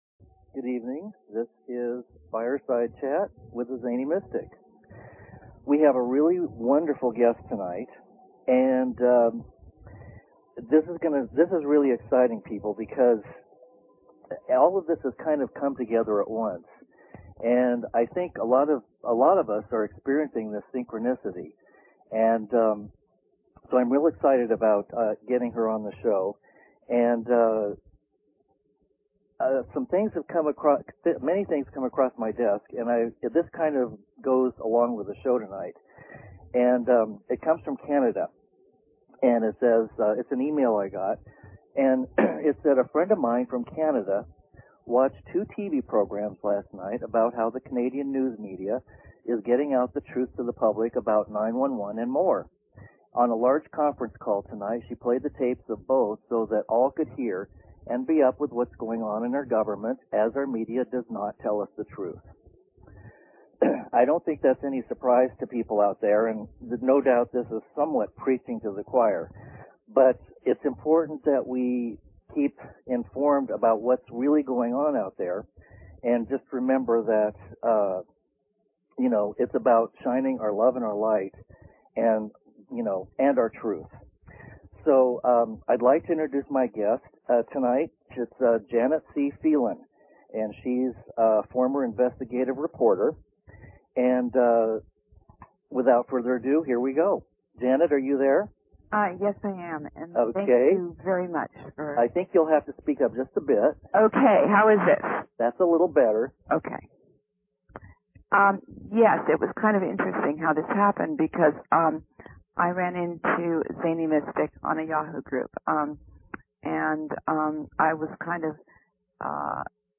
Talk Show Episode
I couldn't hear her through much of the show, so I chose to "wing it" for an hour, rather than stopping the show.